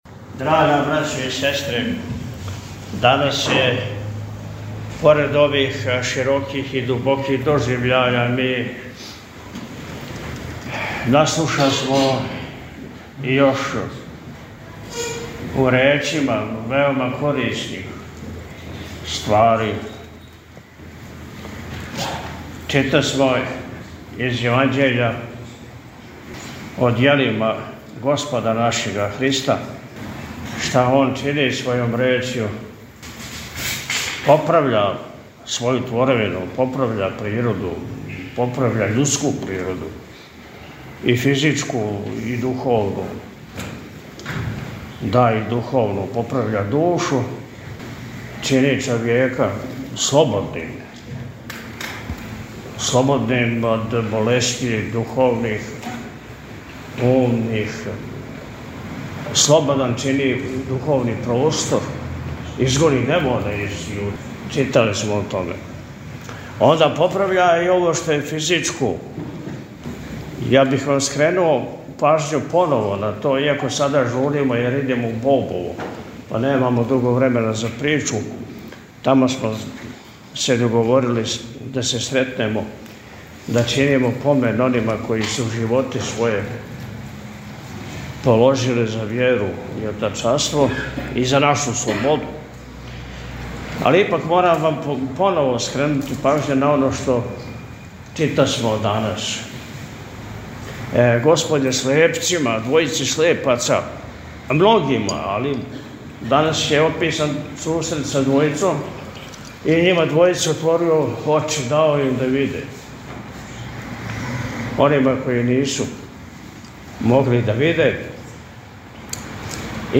По отпусту Високопреосвећени се сабраном верном народу обратио пригодном пастирском беседом: – Читасмо из Јеванђеља о делима Господа нашега Христа, шта Он чини својом Речју.
Бесједа-владика-Атанасије-Пљевља-27.-јул.mp3